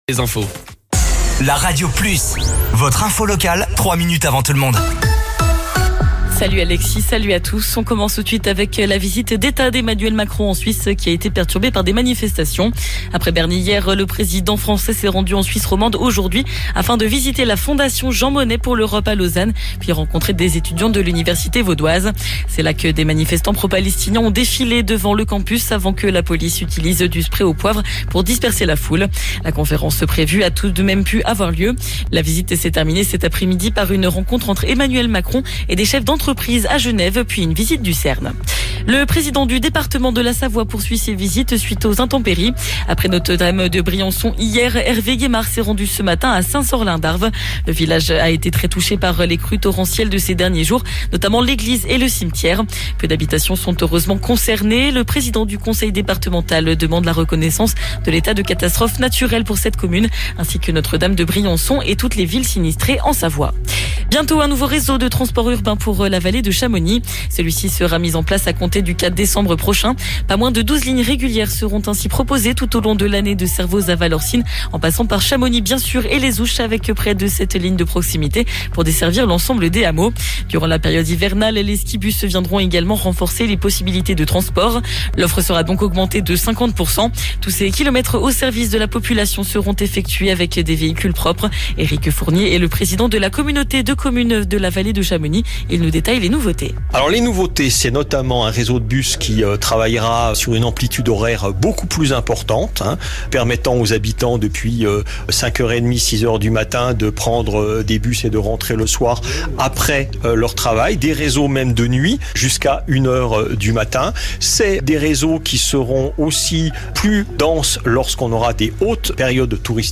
Flash Info
Votre flash info - votre journal d'information sur La Radio Plus